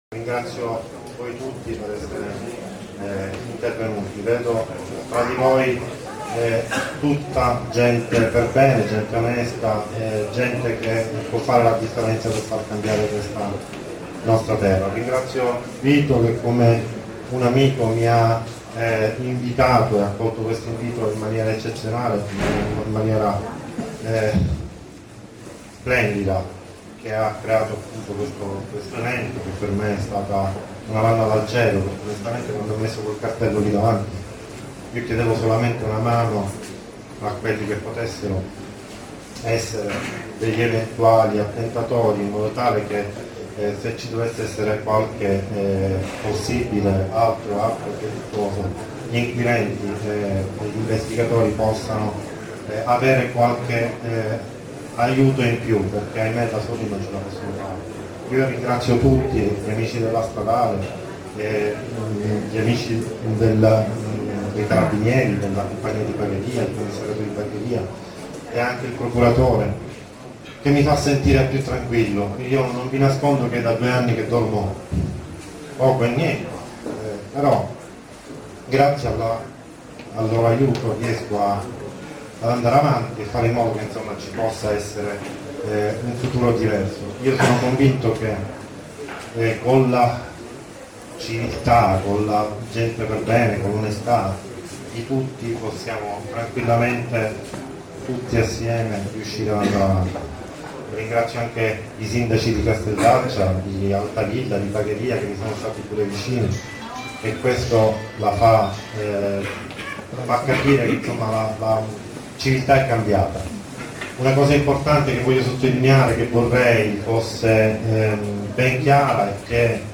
ore 17 presso il Centro Esposizione Calicar (via Gelsi Neri, svincolo autostradale di Altavilla Milicia - Pa)